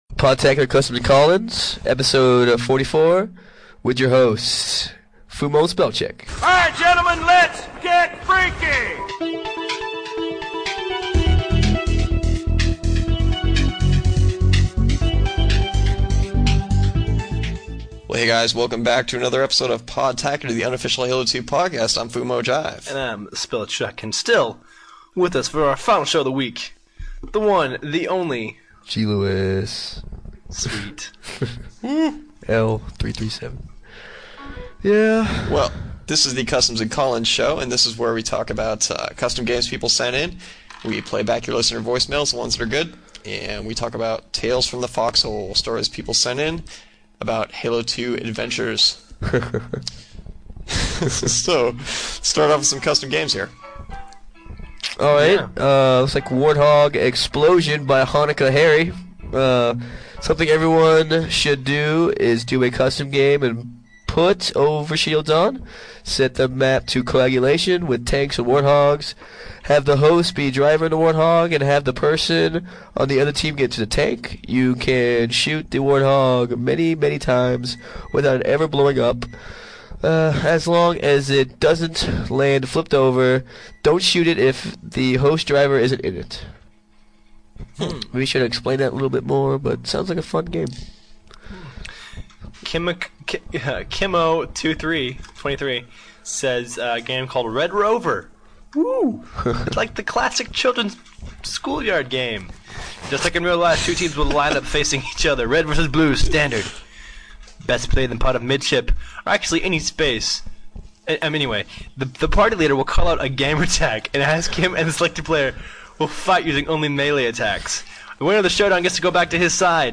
The Customs and Call-ins show, where we cover some cool custom games, tales from the fox hole, tons of voice mails, and shoutouts.